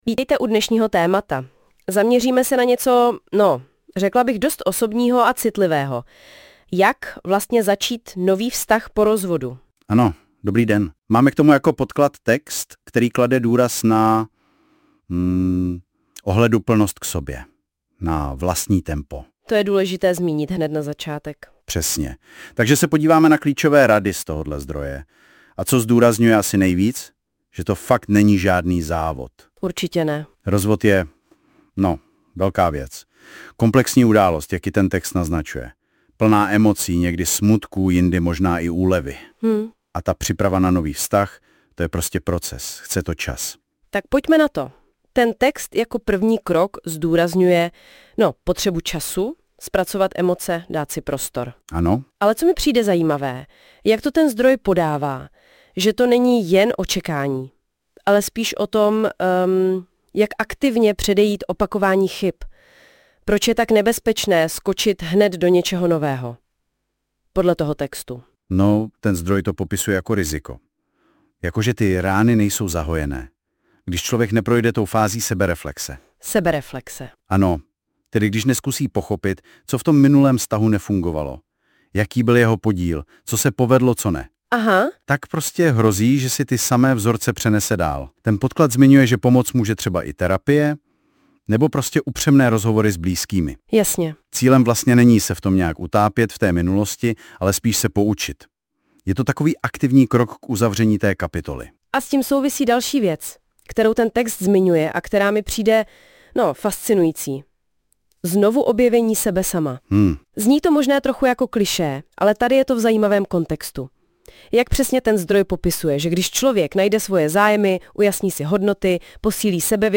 Audioverze ve formátu podcastu vytvořená na základě tohoto článku pomocí nástroje NotebookLM.